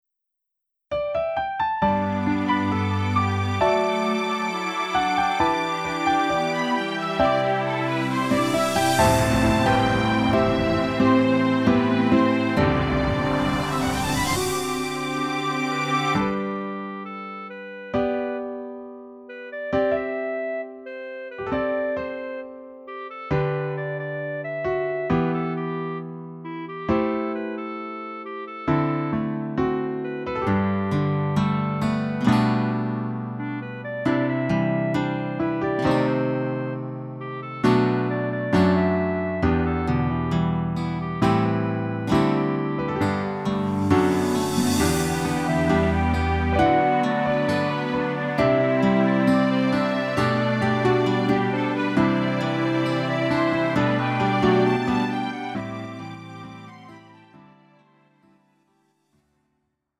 음정 원키 4:00
장르 가요 구분 Lite MR